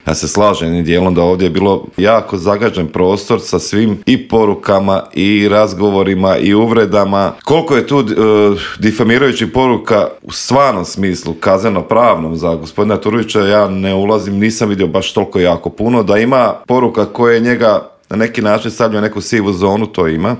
Samo su neka od pitanja na koje smo u Intervjuu Media servisa odgovore potražili od saborskog zastupnika iz Domovinskog pokreta Marija Radića koji je najavio veliki politički skup stranke 23. ožujka u Zagrebu.